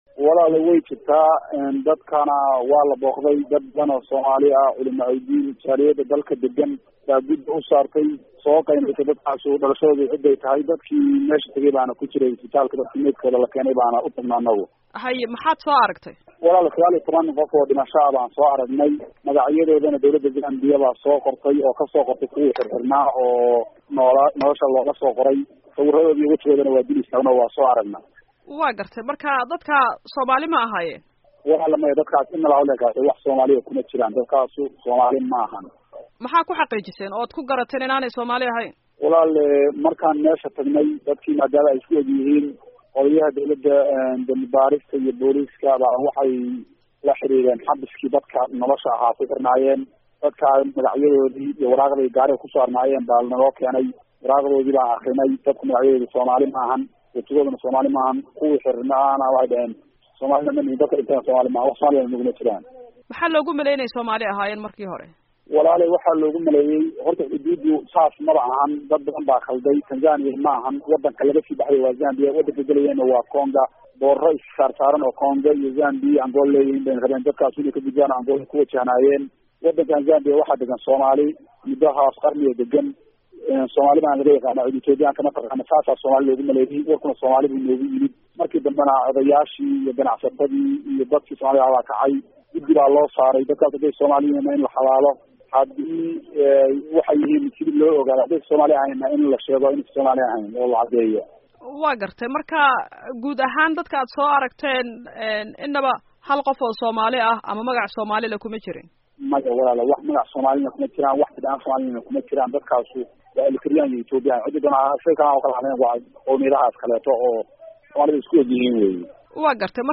Wareysi: Dad ku dhintay Zambia